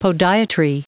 Prononciation du mot podiatry en anglais (fichier audio)
Prononciation du mot : podiatry